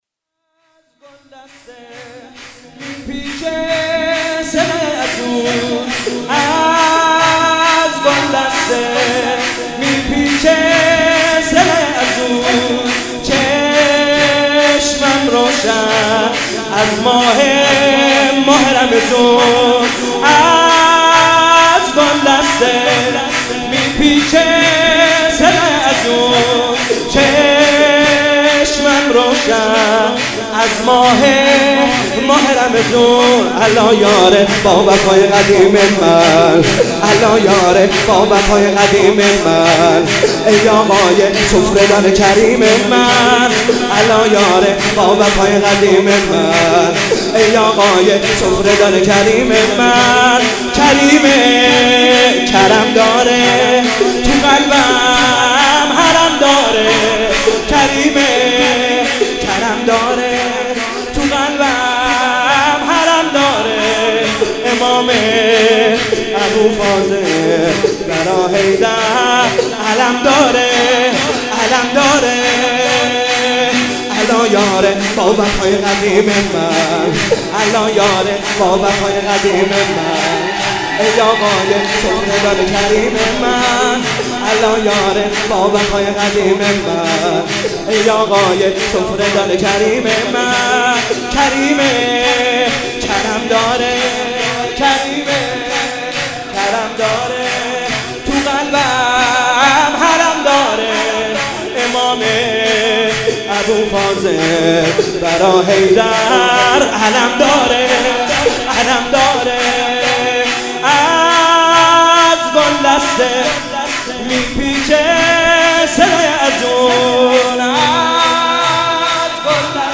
مناسبت : شب پانزدهم رمضان - ولادت امام حسن مجتبی علیه‌السلام
قالب : شور